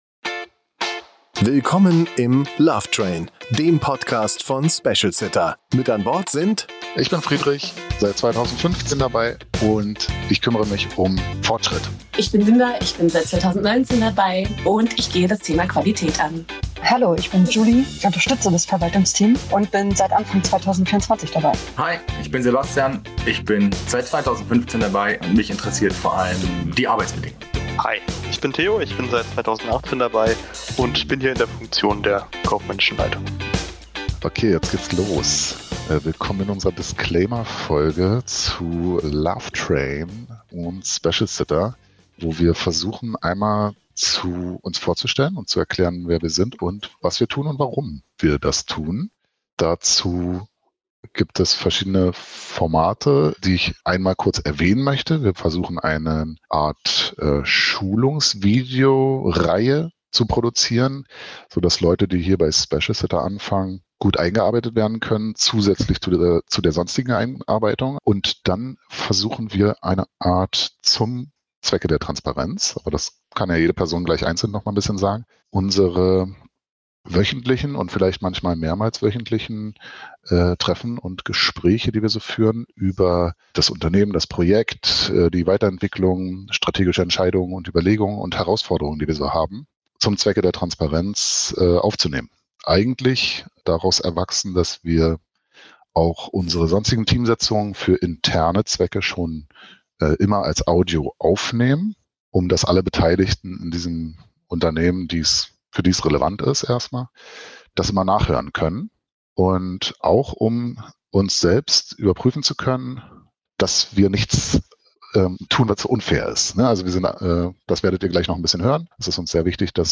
Unsere fünf Hauptpersonen des Podcasts stellen sich vor und erklären was dieser Podcast soll, wofür er nicht gedacht ist und was Specialsitter überhaupt ist.